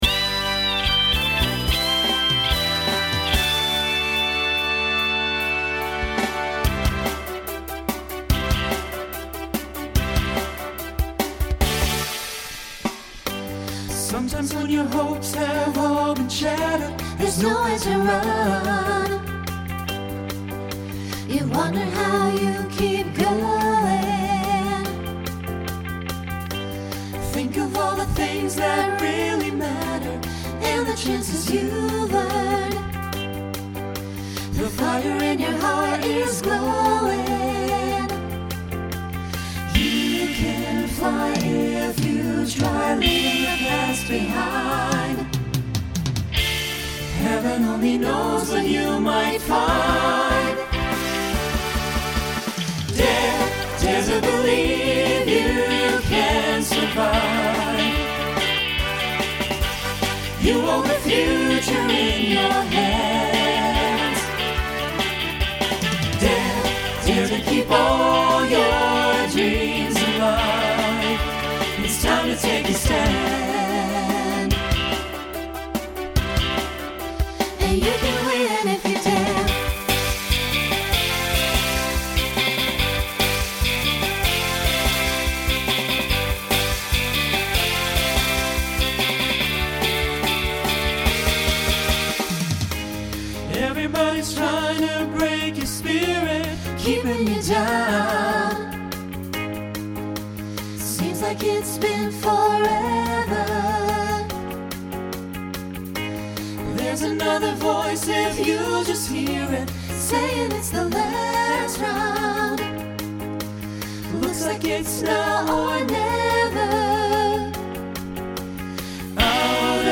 Mixed SATB